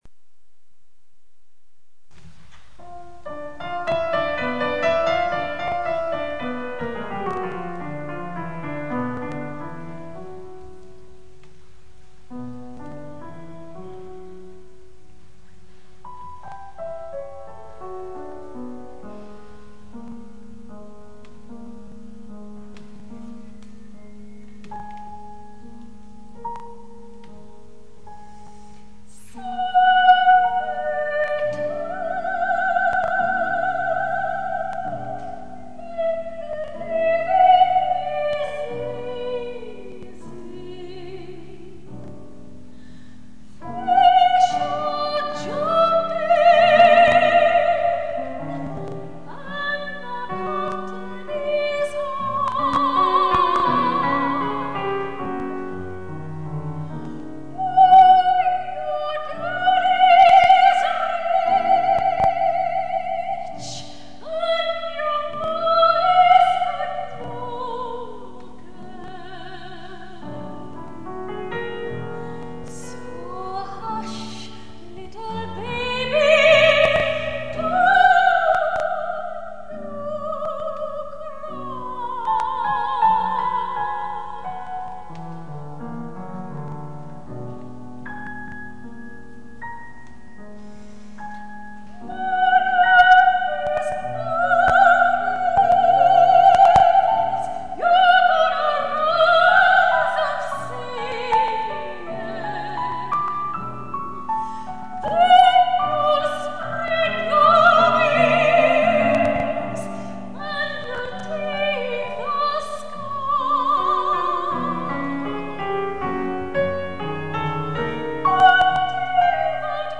soprano
au piano